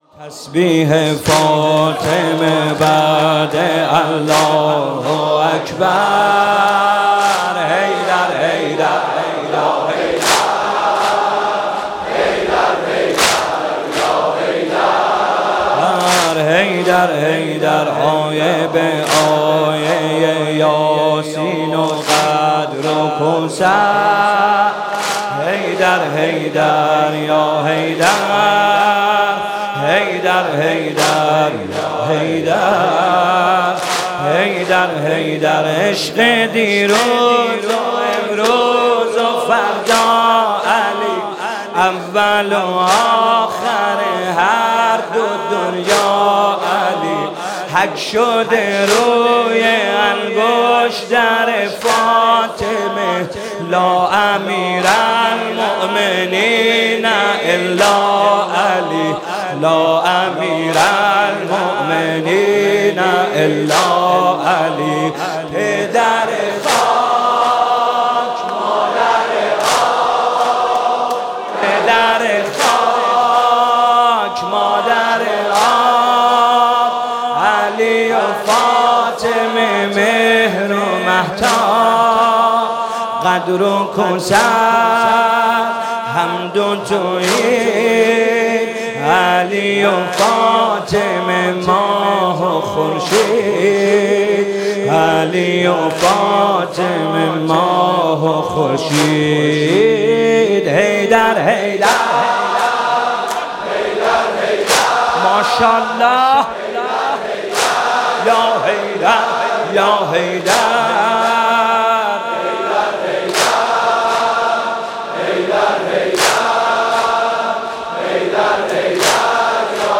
تولید شده: هیئت مکتب الزهرا حسین طاهری